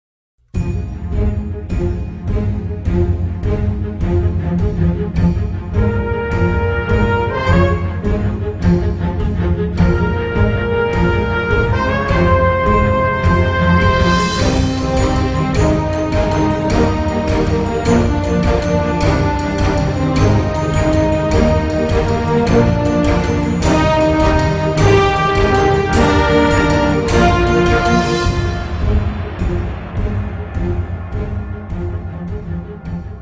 • Качество: 320, Stereo
красивые
без слов
инструментальные
оркестр